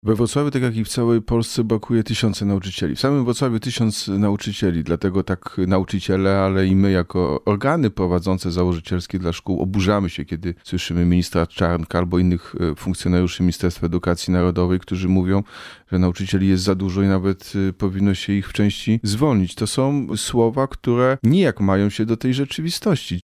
„To jest wyzwanie dla kolejnego rządu, aby wreszcie przywrócić należną rolę szkole i należny status nauczycielom” – mówił na antenie Radia Rodzina prezydent Wrocławia, Jacek Sutryk, w przededniu Dnia Edukacji Narodowej.
W samym Wrocławiu brakuje blisko tysiąca nauczycieli. – mówi włodarz miasta, Jacek Sutryk.